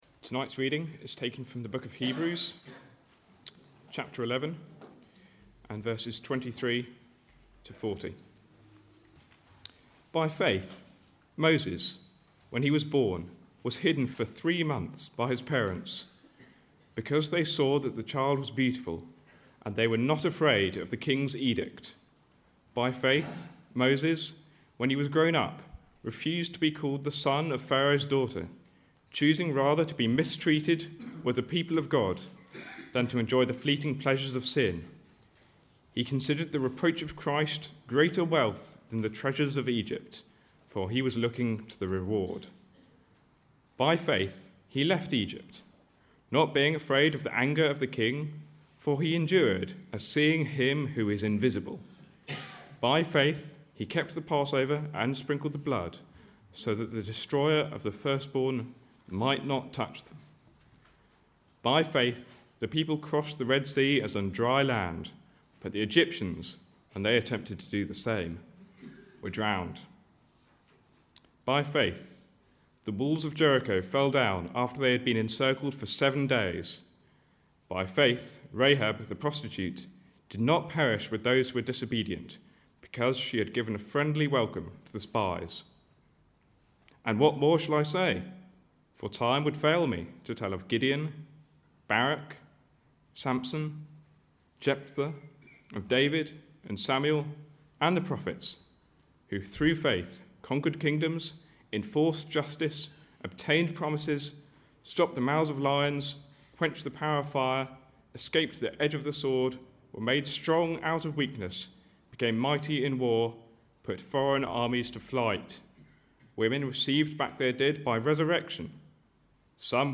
2017 Autumn Lectures